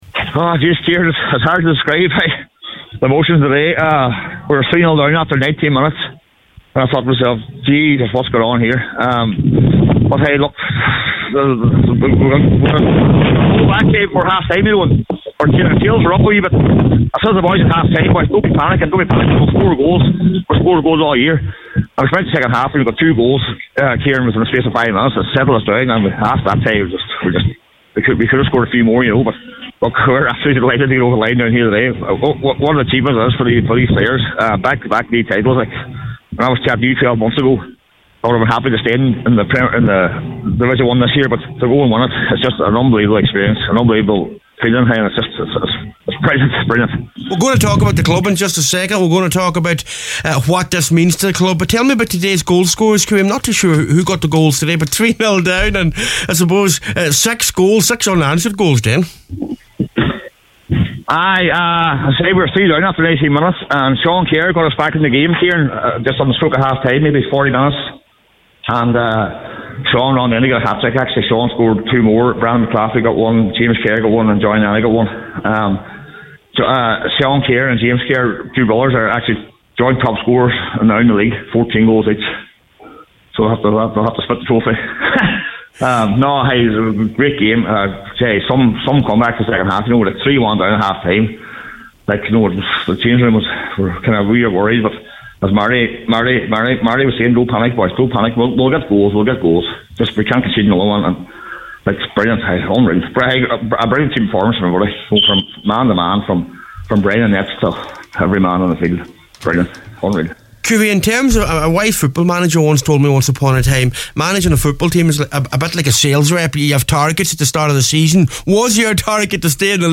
during the celebrations at Rab’s Park…